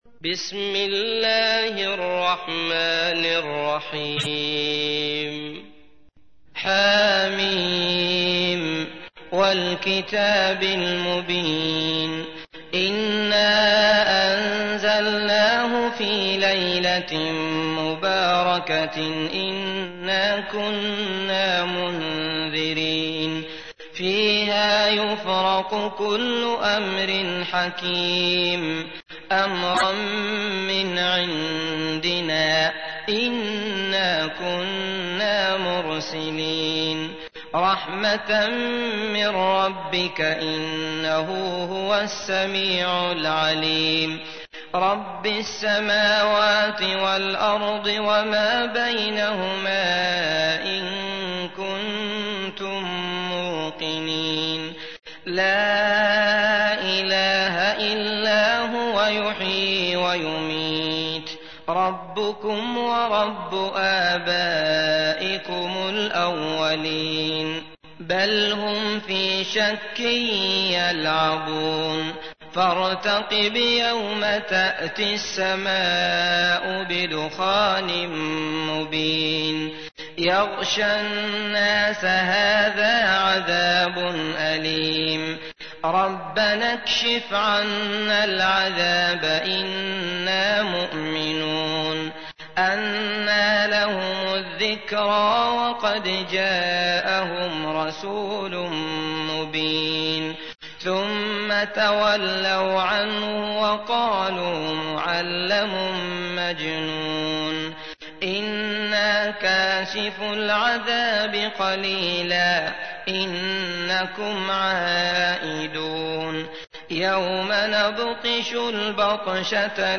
تحميل : 44. سورة الدخان / القارئ عبد الله المطرود / القرآن الكريم / موقع يا حسين